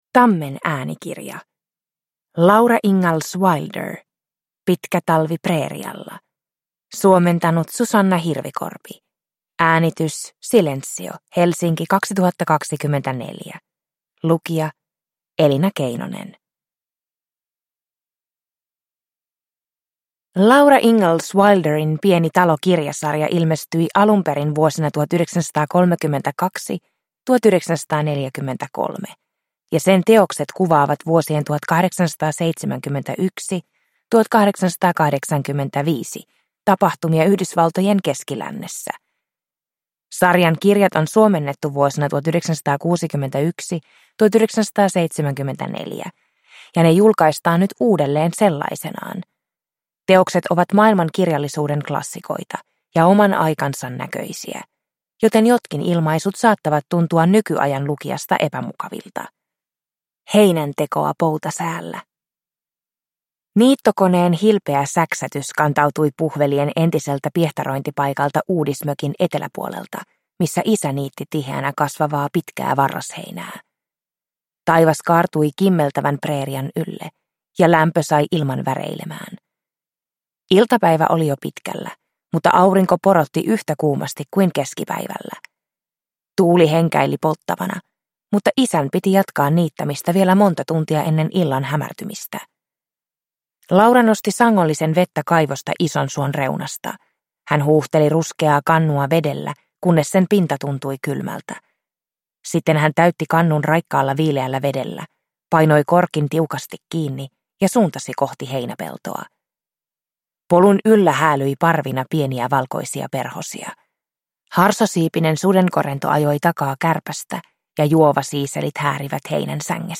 Pitkä talvi preerialla – Ljudbok